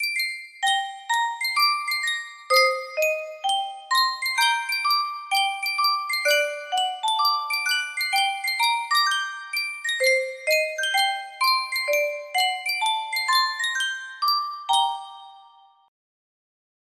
Yunsheng Music Box - Song of the Volga Boatmen 5733 music box melody
Full range 60